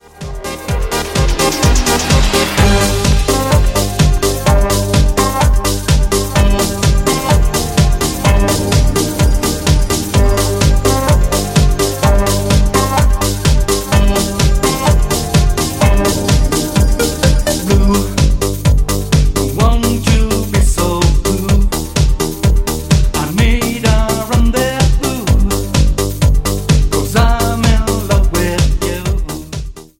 DANCE  (06.13)